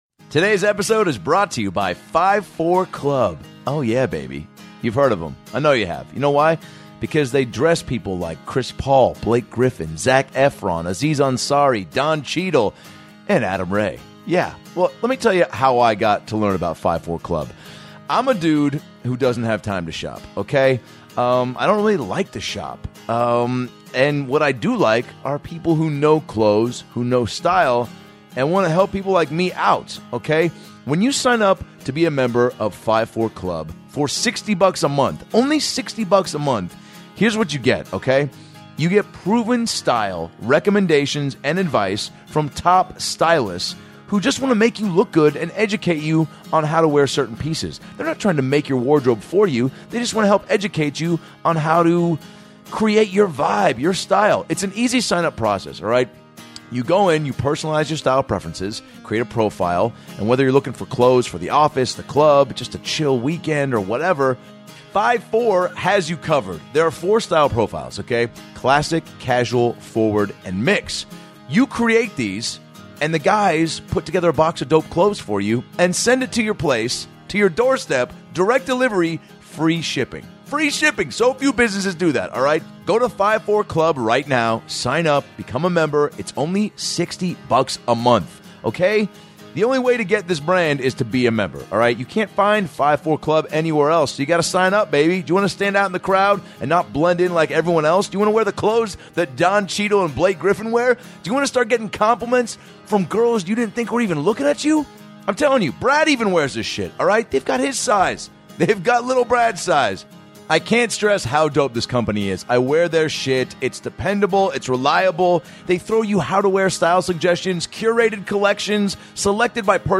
Justin tells us how YOUTUBE helped kickstart his career, making kids laugh on the playground doing Bill Cosby impressions, and how he turned down a job as an MTV VJ, to make the move to LA. He's a rising star, with strong comedic chops, and was so fun to interview.